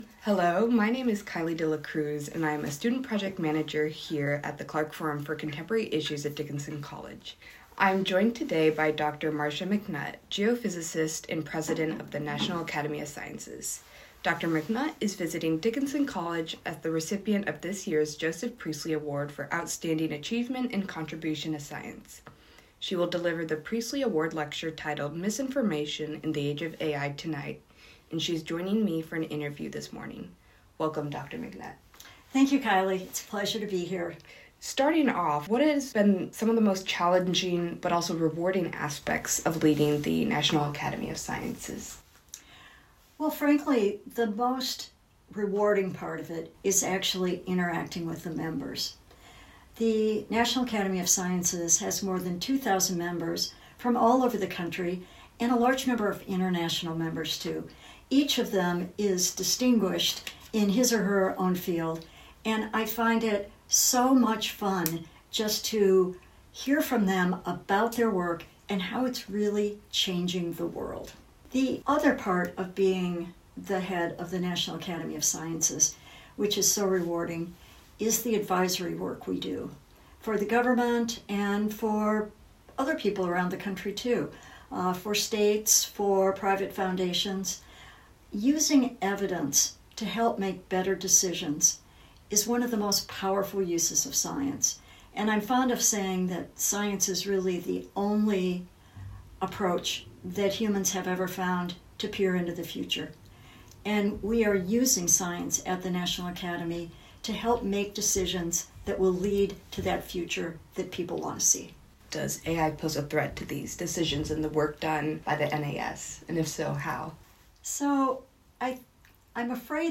Interview with Marcia McNutt , President of the National Academy of Sciences | Clarke Forum for Contemporary Issues